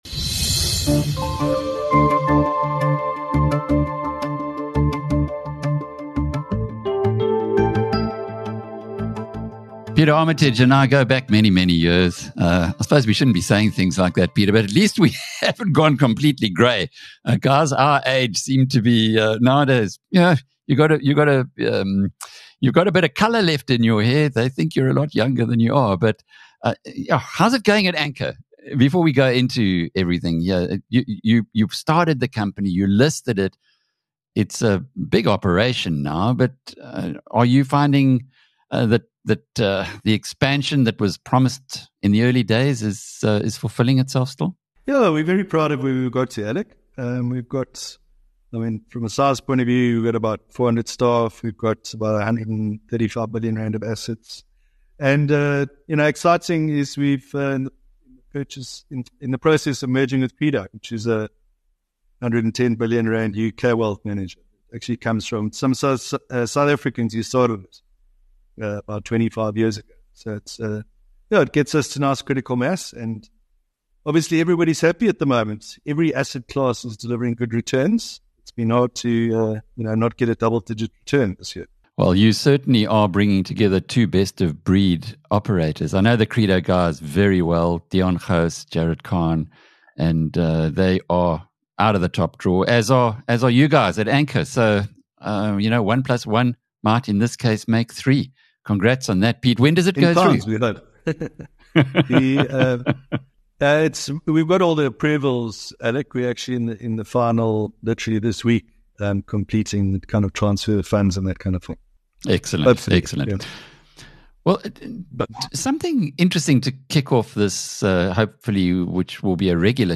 In a recent interview